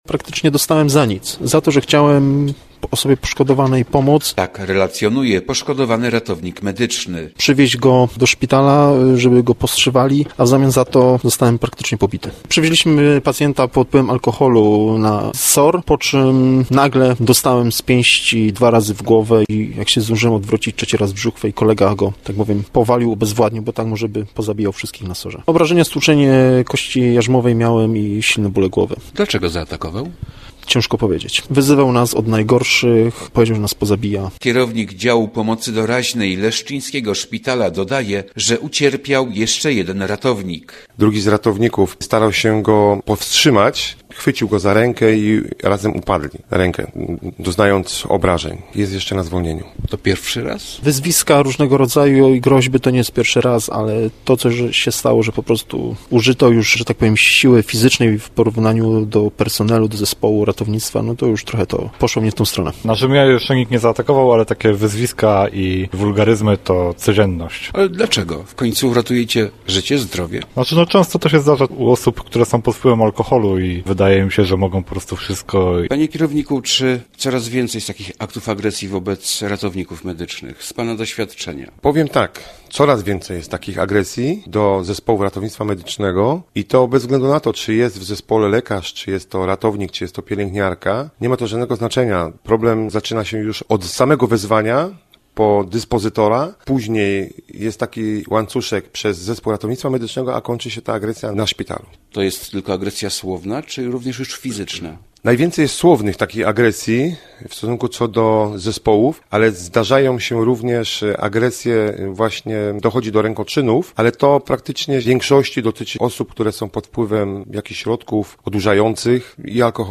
- Nic złego temu pacjentowi nie zrobiliśmy - mówi poszkodowany ratownik.